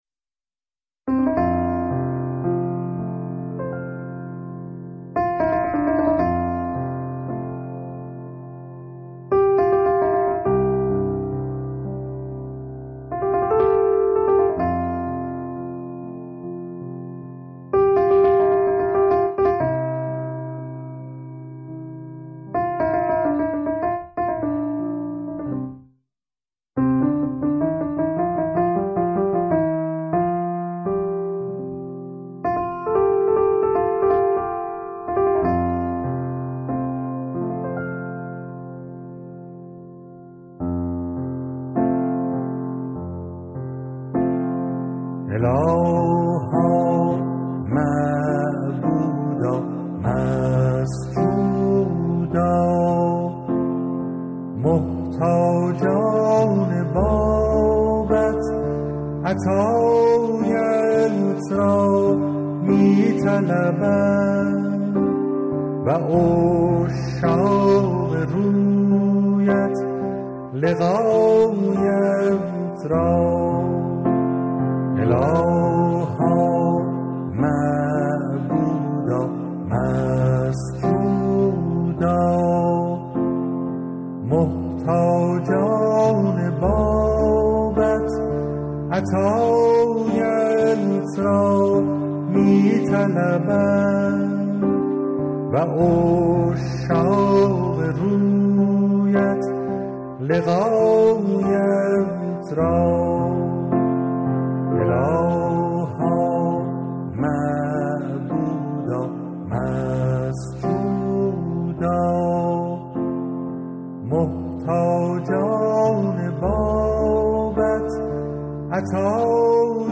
اذکار فارسی (آوازهای خوش جانان)